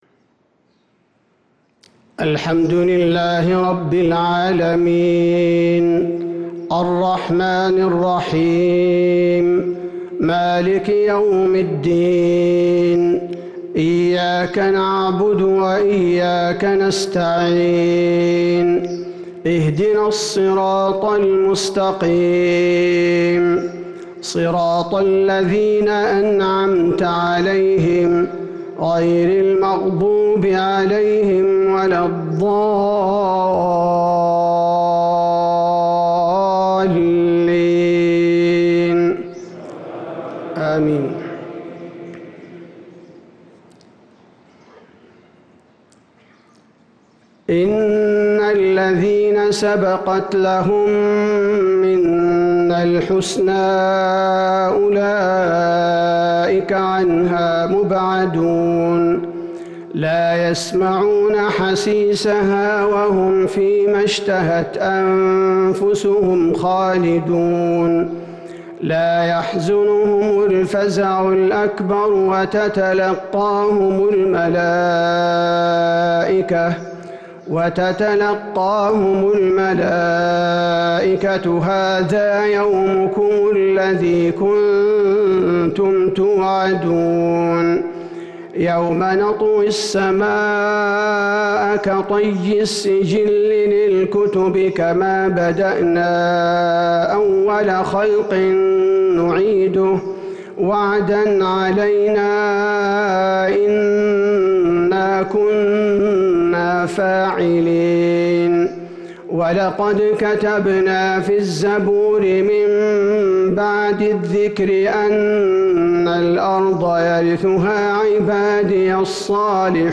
فجر ٨ رمضان ١٤٤٣هـ من سورة الأنبياء |fagir prayer from Surah al-Anbiya 9-4-2022 > 1443 🕌 > الفروض - تلاوات الحرمين